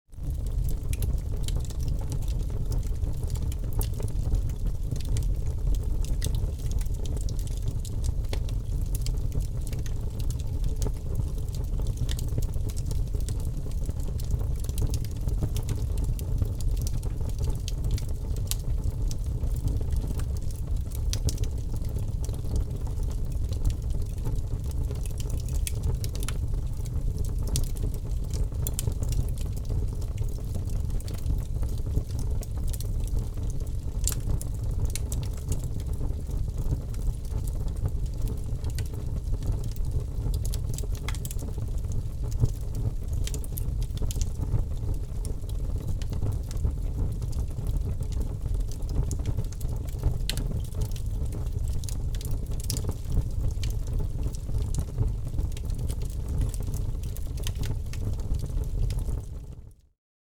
Звуки горящего камина
Когда камин уже разгорелся, появляется ровный, почти гипнотический гул.
• Категория: Камин
На этой странице вы можете прослушать звуки горящего камина.